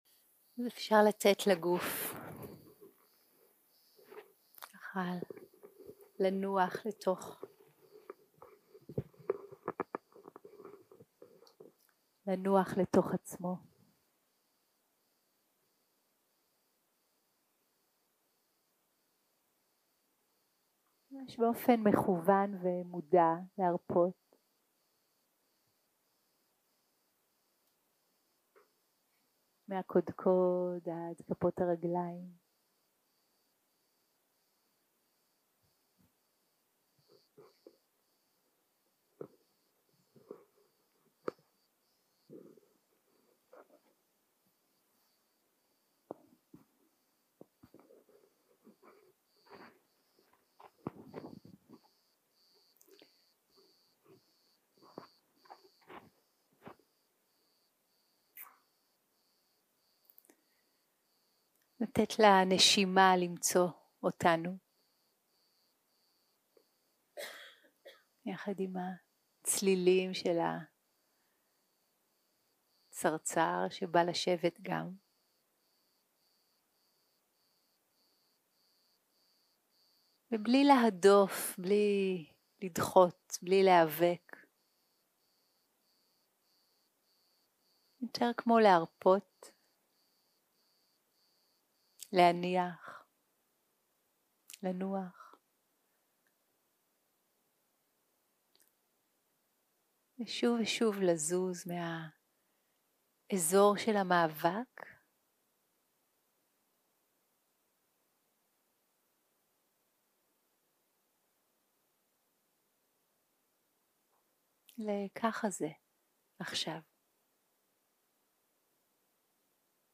הקלטה 2 - יום 1 - ערב - מדיטציה מונחית
Dharma type: Guided meditation